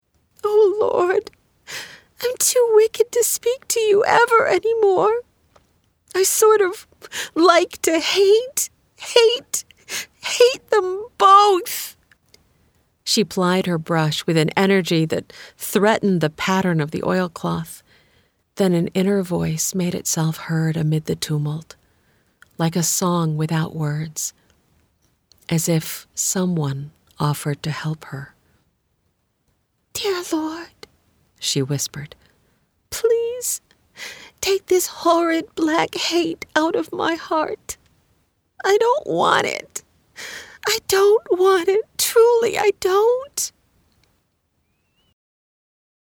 Audiobook: House of Love - MP3 download
This is an audiobook, not a Lamplighter Theatre drama.
House-of-Love-Audiobook-Sample.mp3